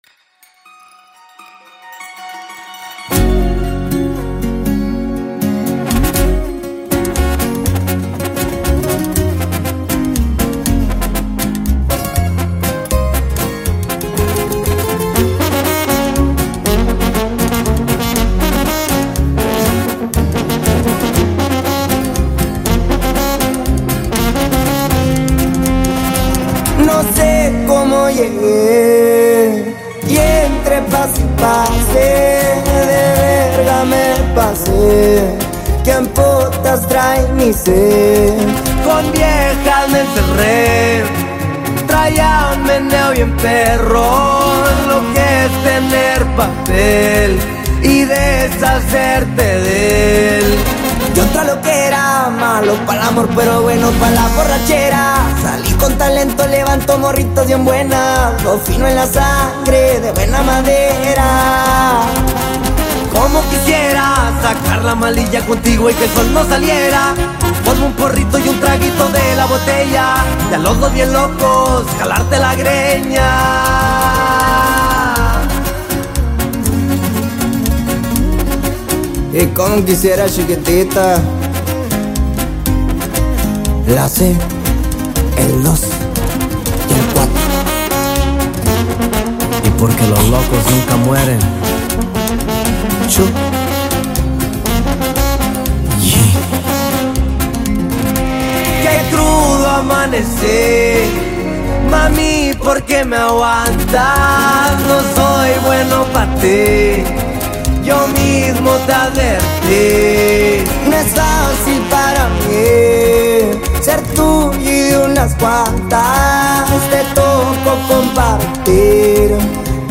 It delivers a sound that stays consistent throughout.